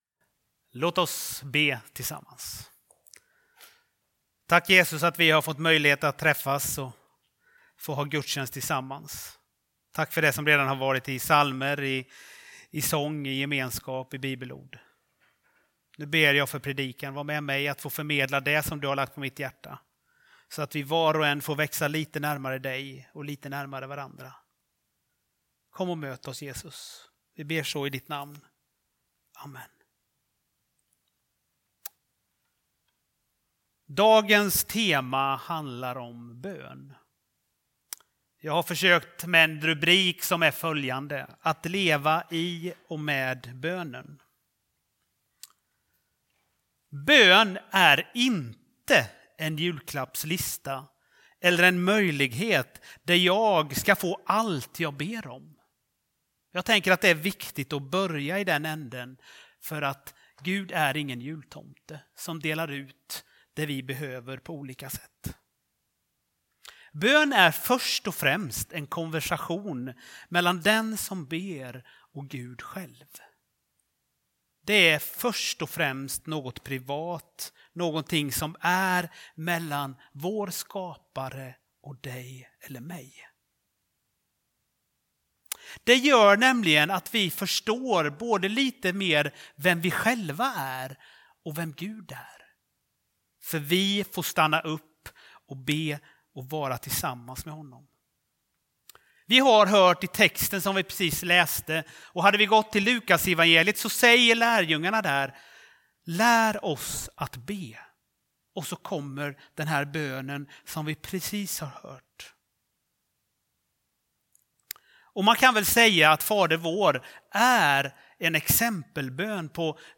Gudstjänst i Centrumkyrkan i Mariannelund